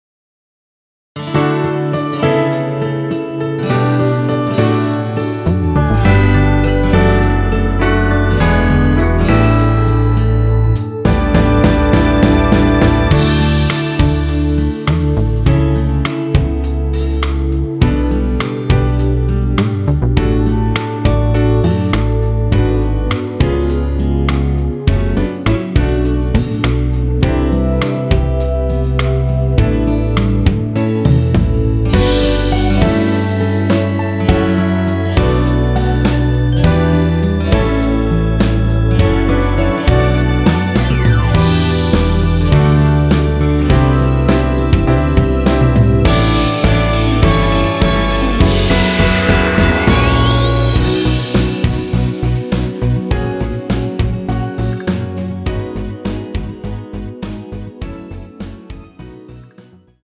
MR입니다.
앞부분30초, 뒷부분30초씩 편집해서 올려 드리고 있습니다.